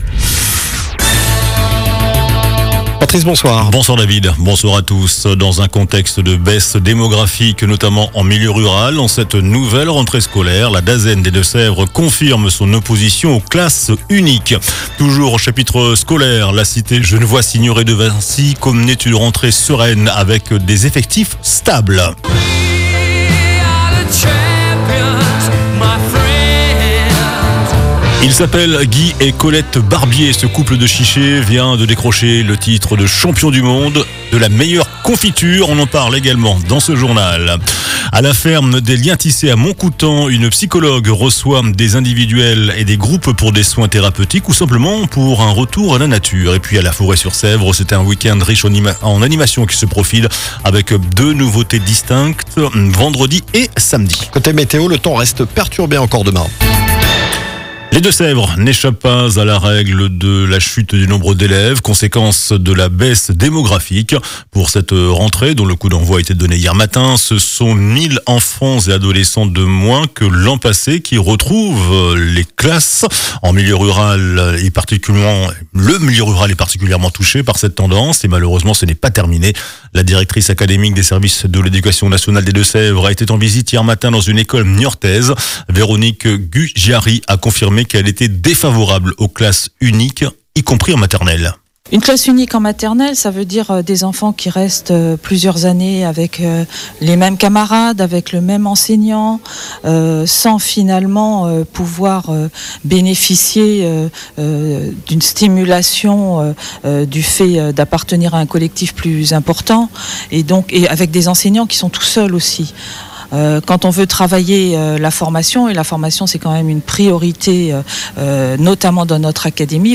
JOURNAL DU MARDI 02 SEPTEMBRE ( SOIR )